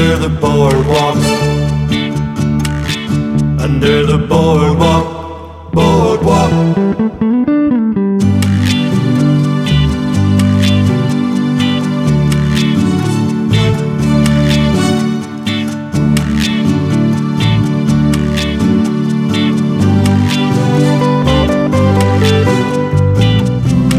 No Guitars Soul / Motown 2:40 Buy £1.50